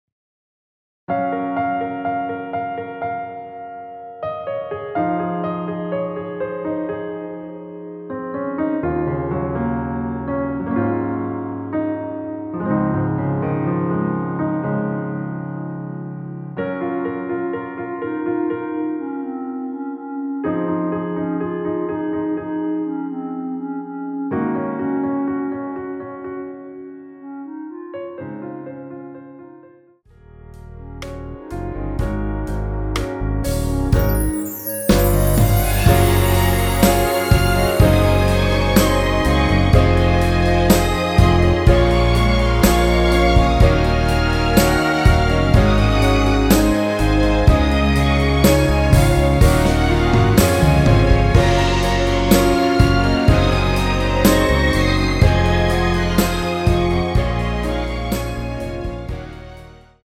원키에서(-7)내린 멜로디 포함된 MR입니다.(미리듣기 확인)
Db
멜로디 MR이란
앞부분30초, 뒷부분30초씩 편집해서 올려 드리고 있습니다.
중간에 음이 끈어지고 다시 나오는 이유는